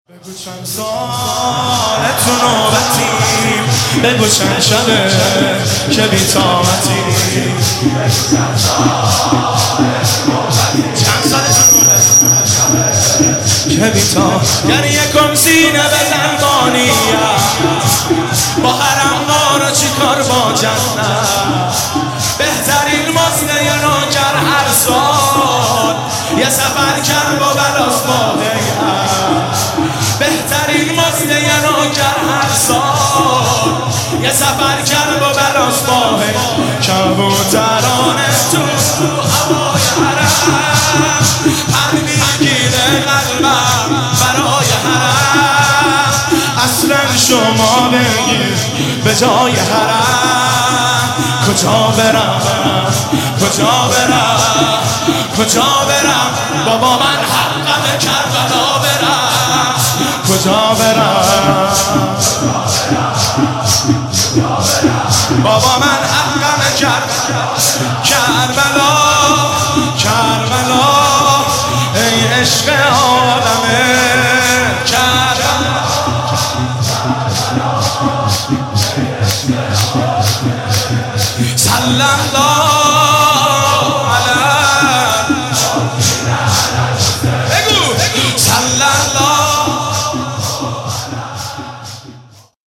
شب هفتم رمضان95، حاج محمدرضا طاهری
زمینه، روضه، مناجات